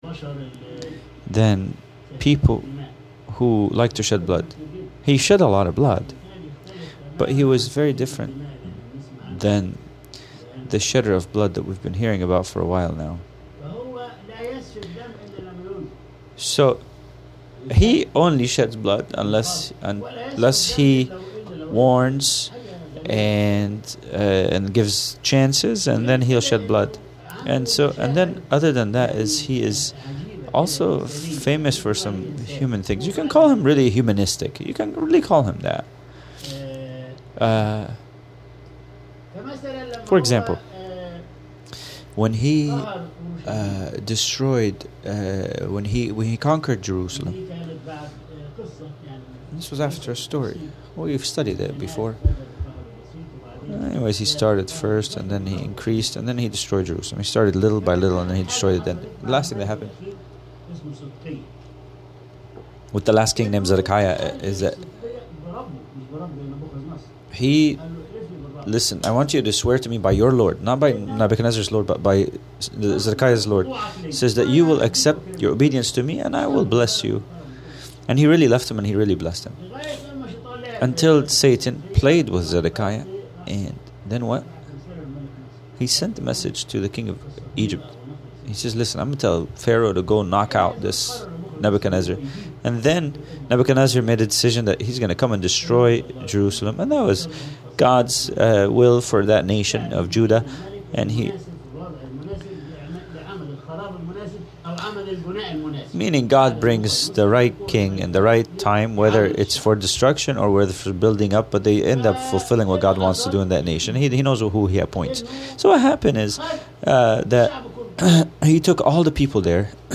Sunday Service | لنأكُل ونشرب